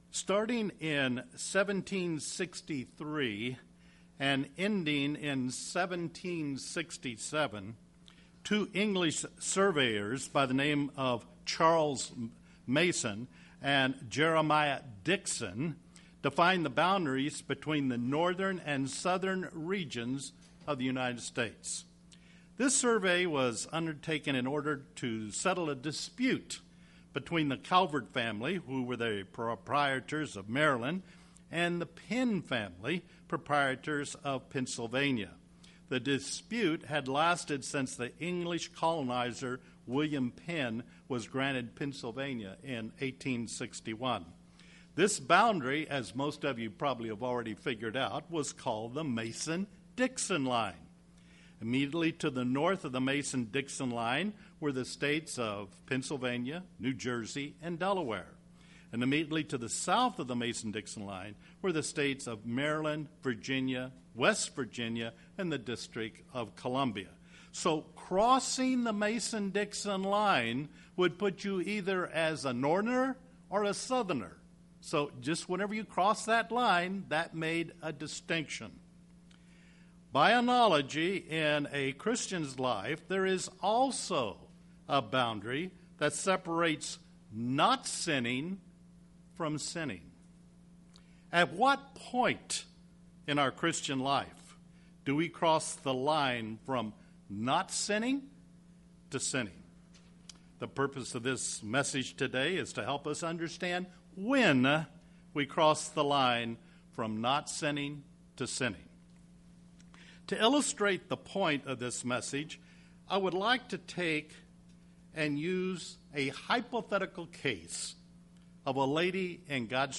Matthew 5:27-30 UCG Sermon Studying the bible?